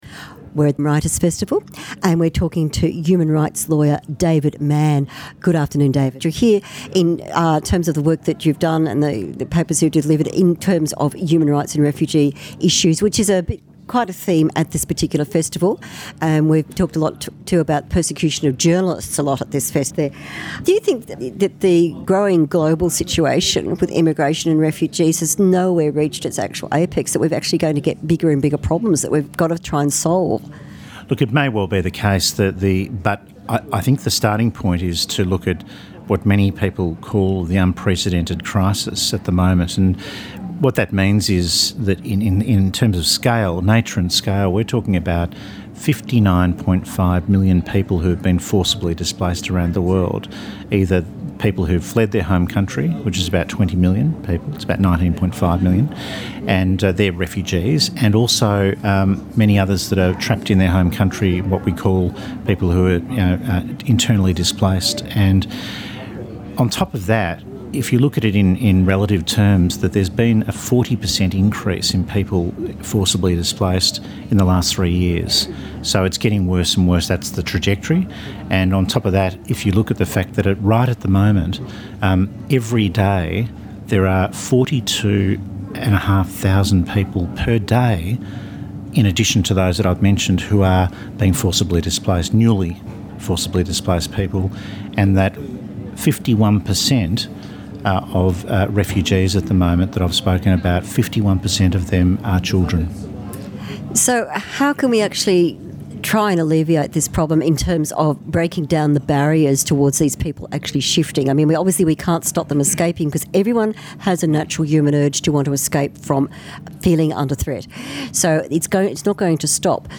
Recorded at Byron  Writers Festival 2015